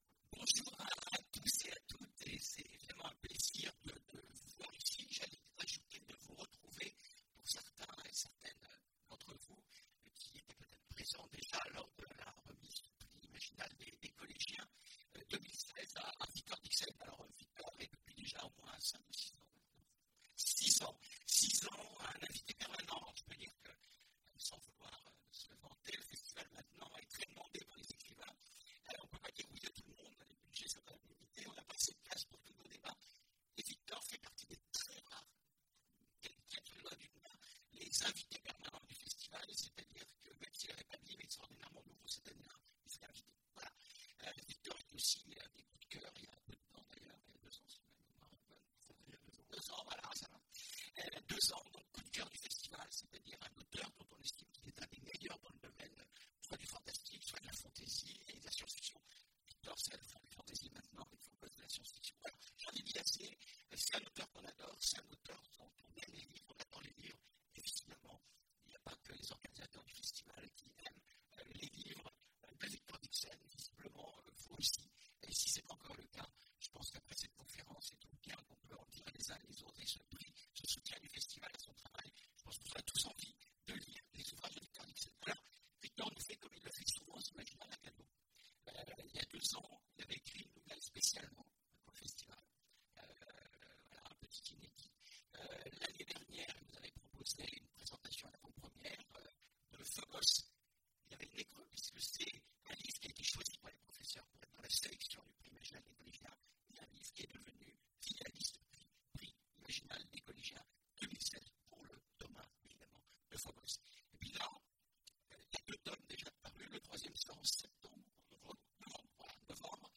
Imaginales 2016 : Présentation de Phobos, les origines et lecture d’extraits de Phobos 3
Imaginales 2016 : Présentation de Phobos, les origines et lecture d’extraits de Phobos 3 Télécharger le MP3 à lire aussi Victor Dixen Genres / Mots-clés Rencontre avec un auteur Conférence Partager cet article